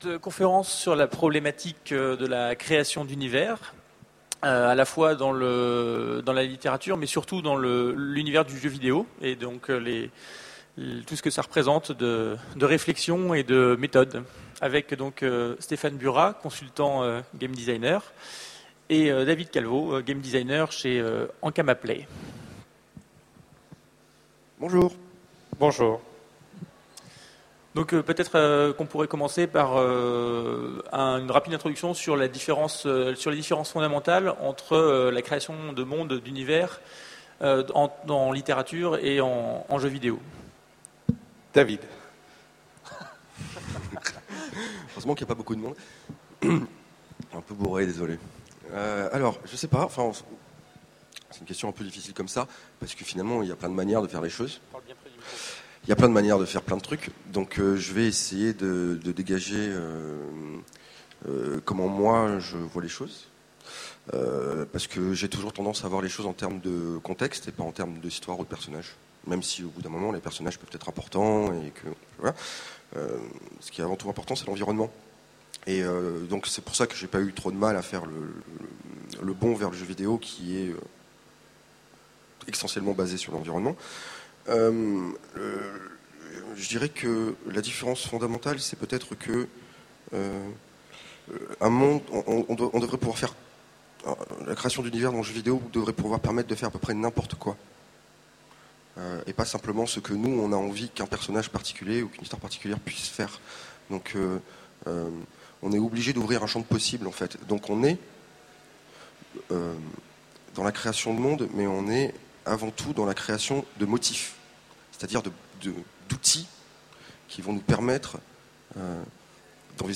Voici l'enregistrement de la conférence Symboles jouables, la création d'univers interactifs pour le jeu vidéo aux Utopiales 2010.